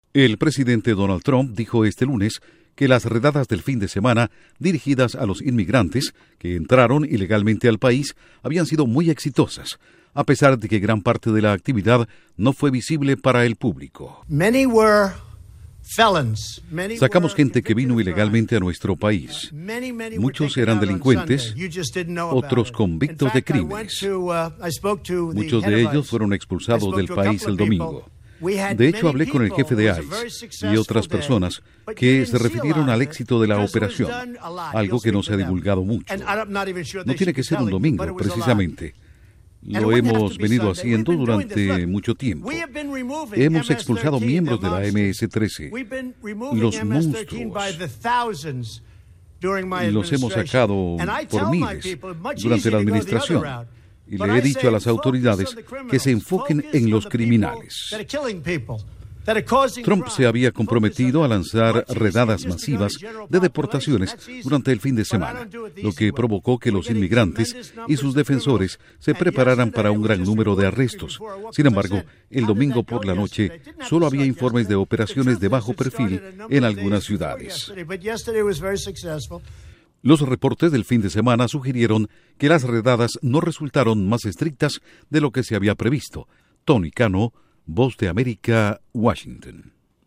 Duración: 1:30 Con declaraciones de Trump